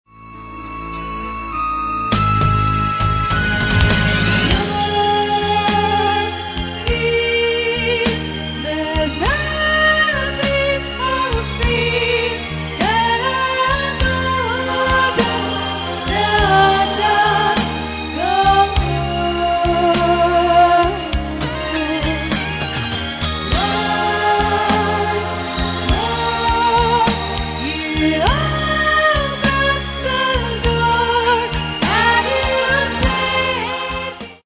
Standard jazz
-élő show jellegű előadás./igény szerint/